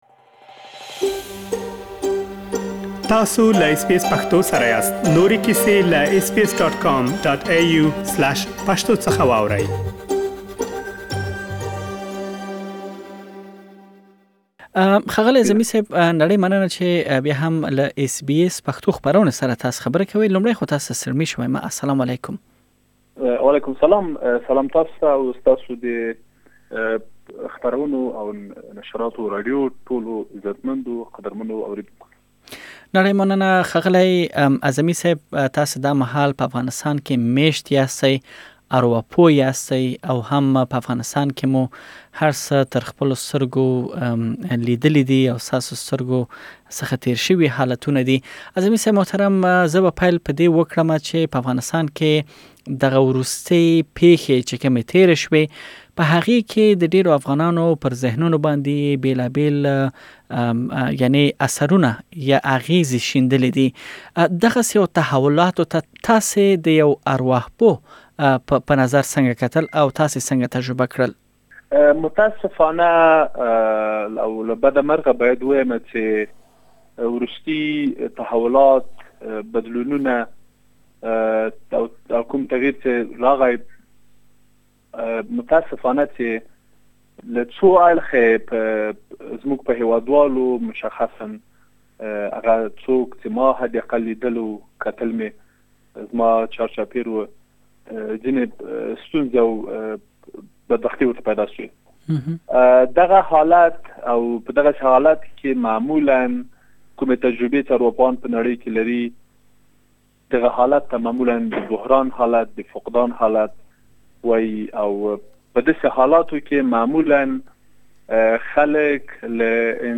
که چيرې د افغانستان بدليدونکي حالت څخه کړيږئ نو دا مرکه له تاسو سره مرسته کولی شي